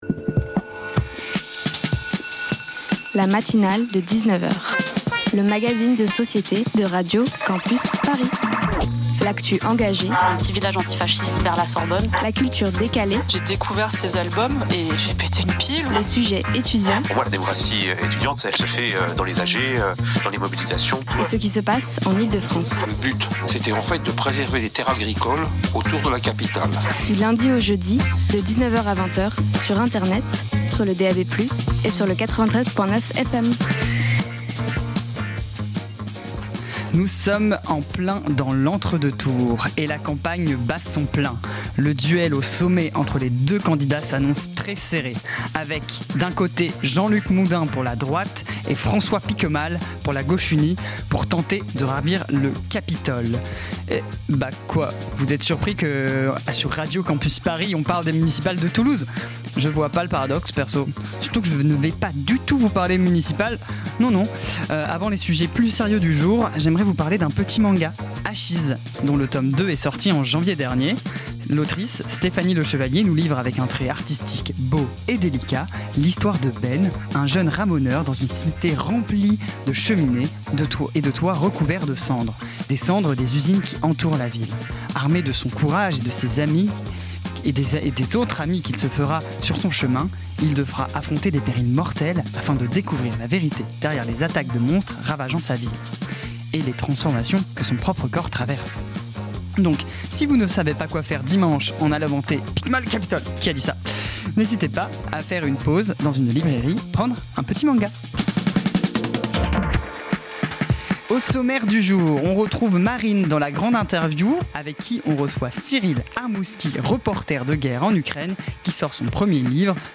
un peuple en guerre & le roman Mœdium Partager Type Magazine Société Culture mercredi 18 mars 2026 Lire Pause Télécharger Ce soir